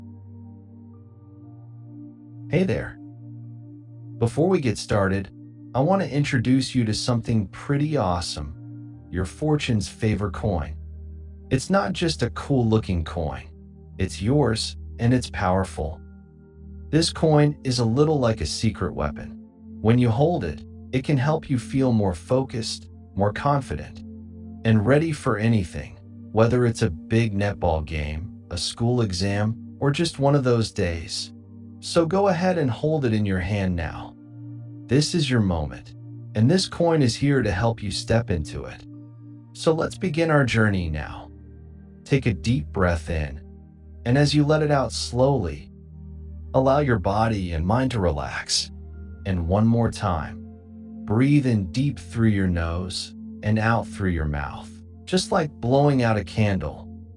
Boost Your Child’s Confidence & Focus with the Fortune’s Favour™ Coin The Fortune’s Favour™ Coin and 5-minute mindfulness audio guide are designed to help young netball players, ages 8 to 15, perform with clarity, confidence, and resilience.